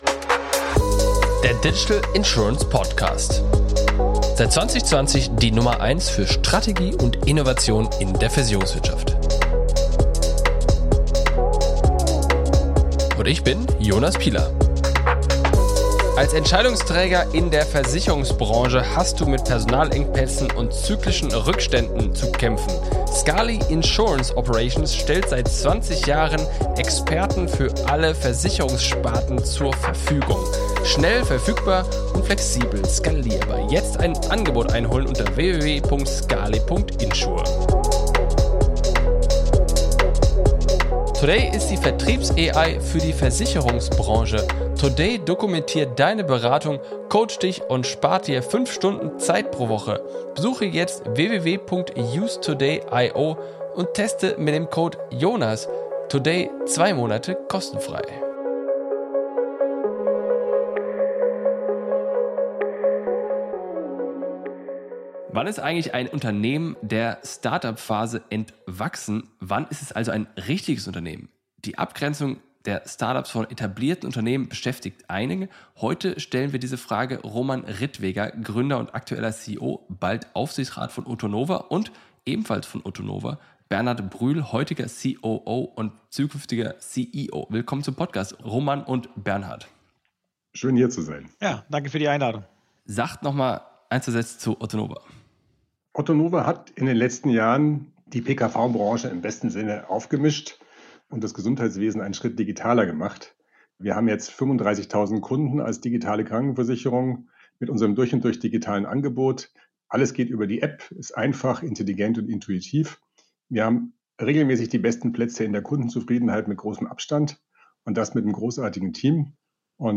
In dieser Folge des Digital Insurance Podcast spreche ich mit